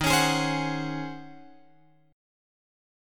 D# Minor 13th